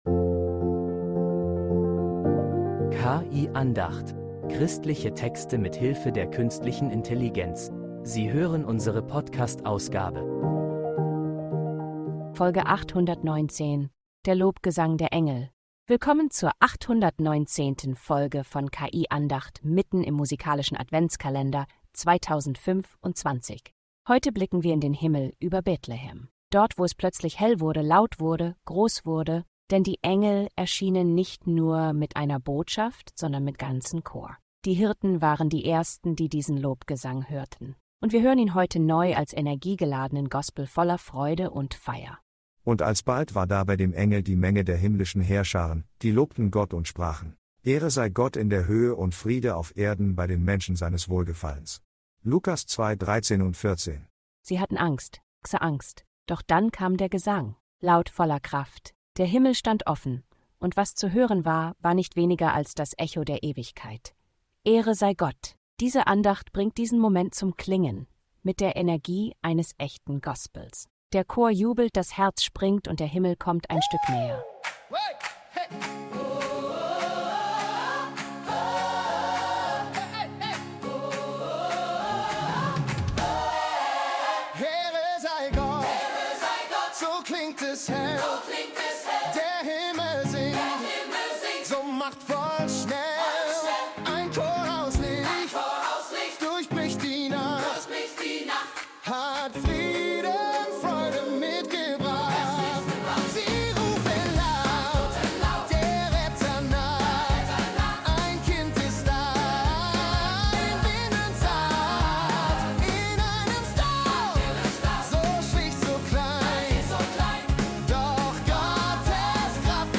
Christliche Texte mit Hilfe der Künstlichen Intelligenz
Die Engel verkünden den Frieden – laut, klar, überwältigend.
dieser Gospel-Andacht erklingt ihr Lobgesang neu: voller Energie